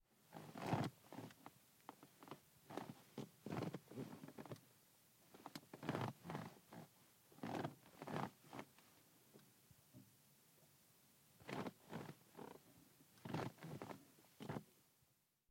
Скрип сиденья в салоне Феррари